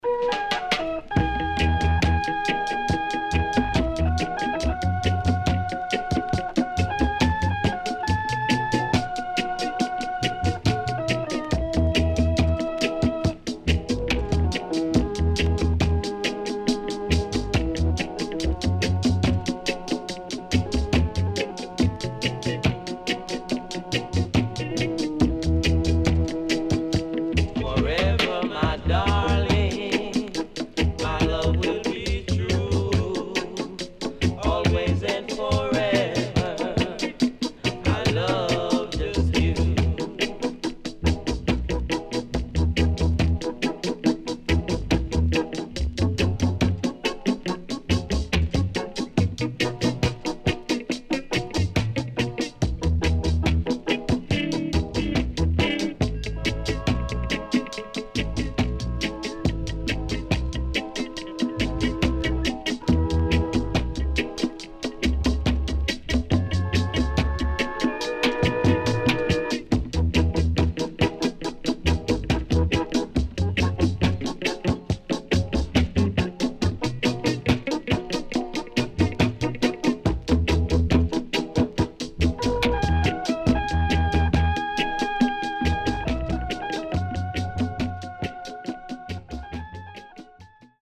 SIDE A:所々プチノイズ入ります。